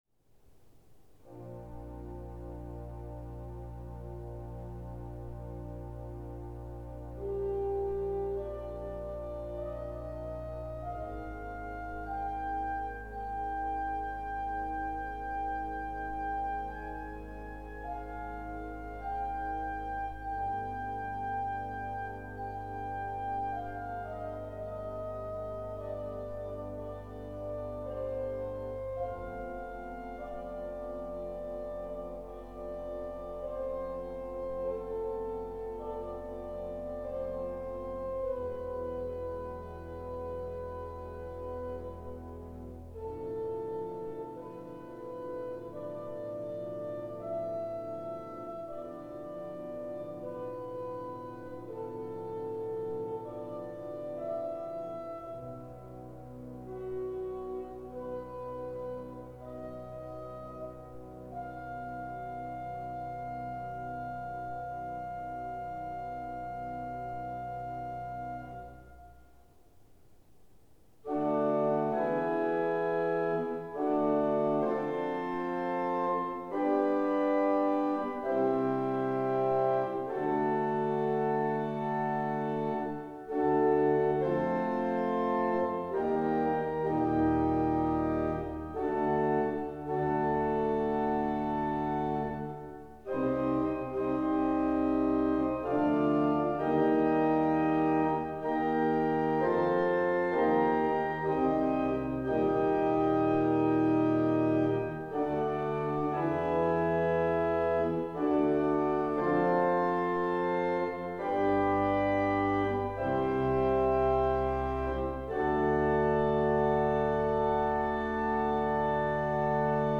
organ Dedication